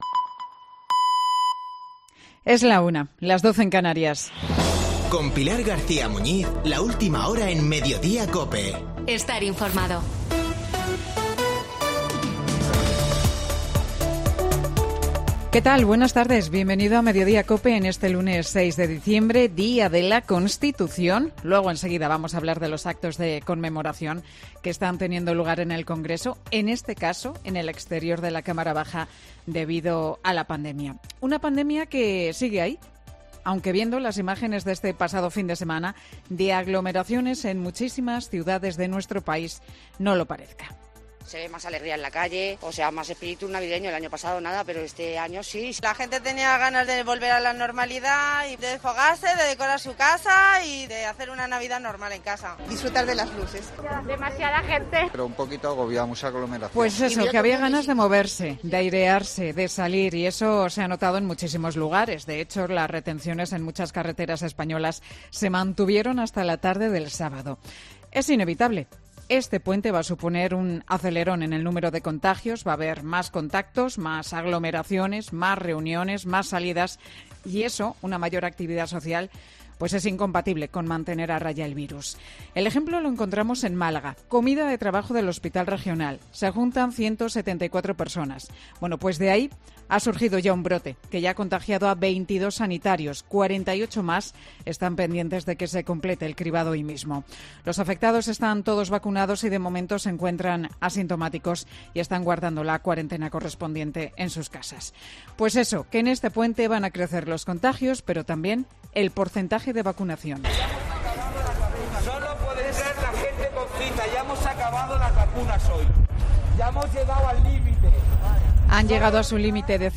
Boletín de noticias COPE del 6 de diciembre de 2021 a las 13.00 horas